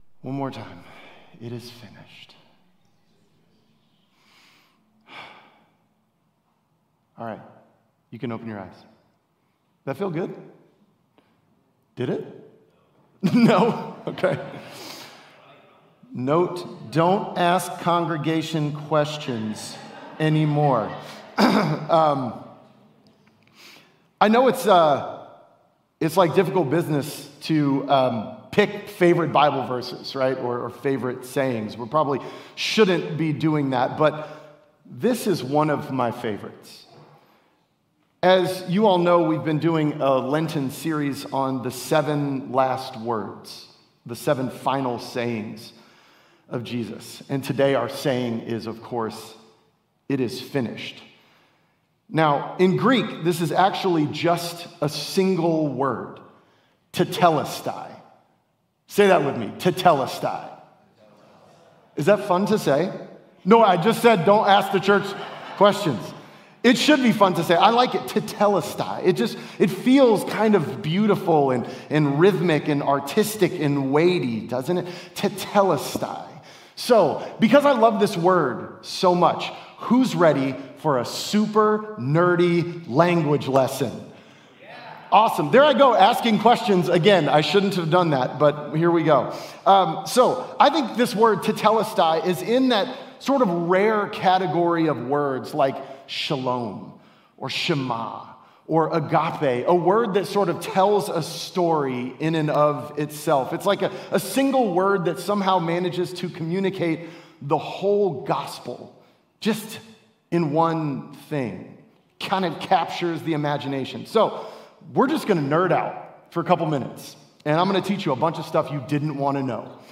Sermons | Ekklesia Church